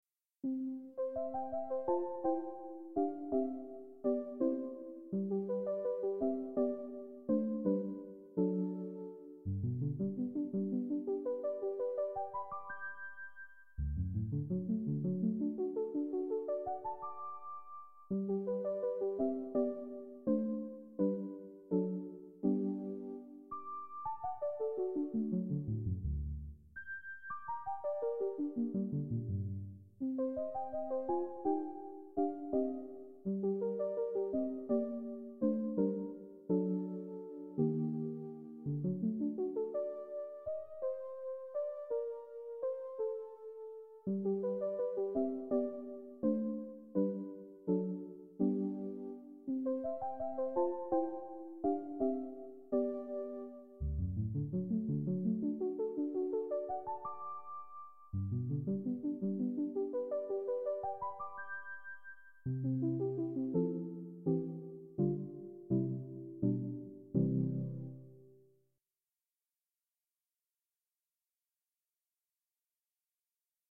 Most of these pieces I performed myself in public concerts.
The sound quality is slightly better on the CD.